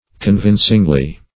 \Con*vin"cing*ly\